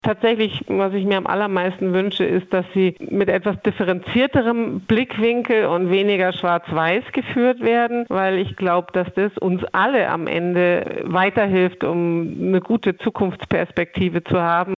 Interview: Bauernverband kritisiert Lidl-Zukunftspläne - PRIMATON